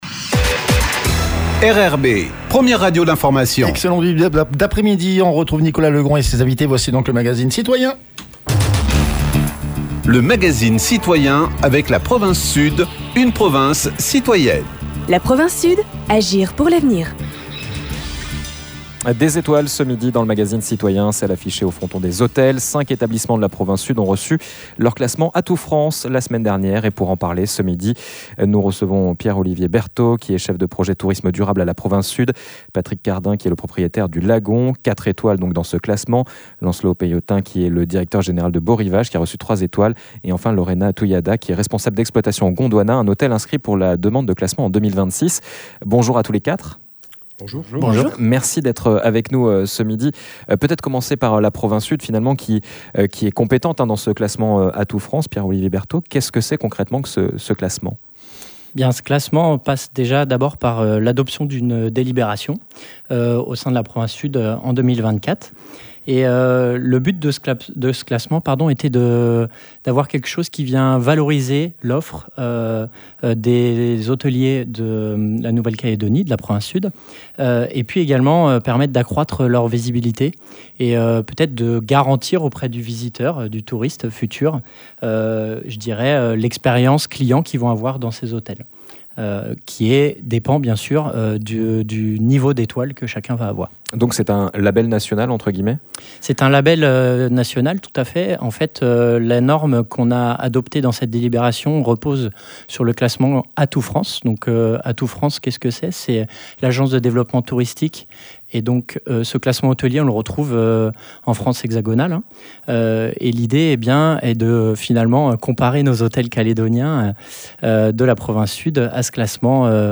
Nous en avons parlé ce midi dans notre magazine citoyen.